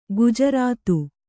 ♪ gujarātu